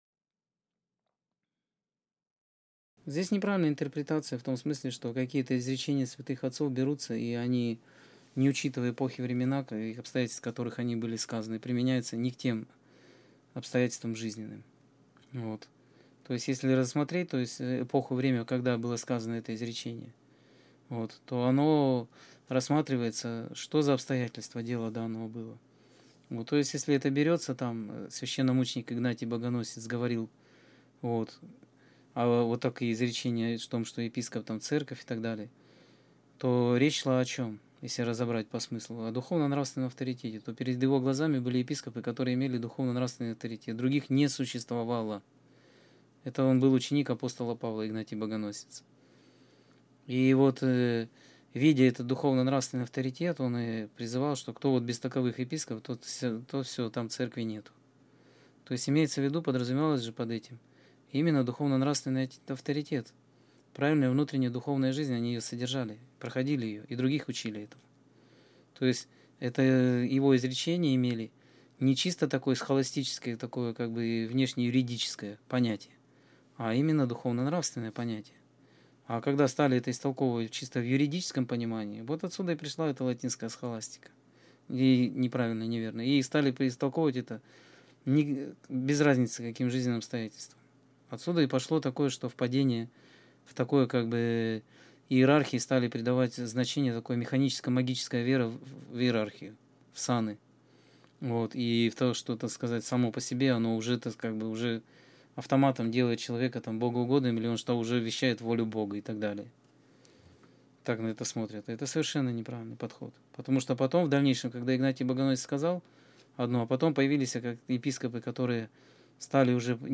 Скайп-беседа 27.05.2017